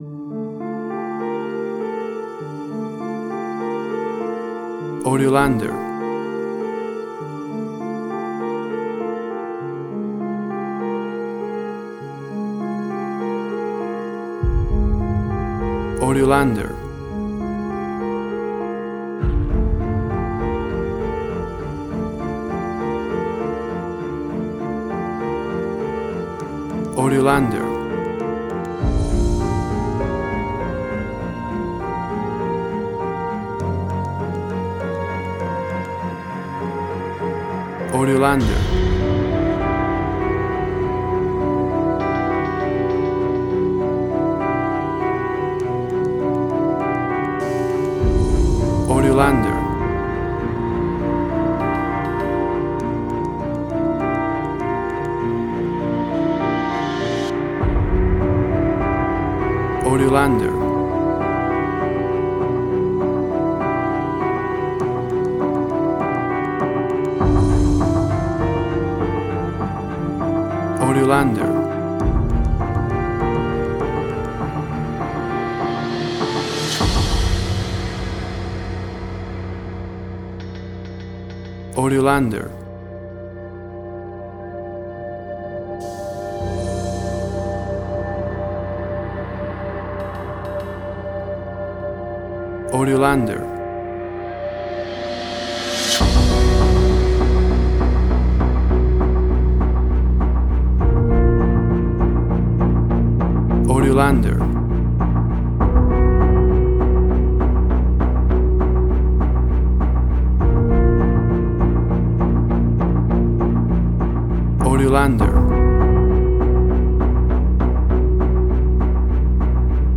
Suspense, Drama, Quirky, Emotional.
Tempo (BPM): 100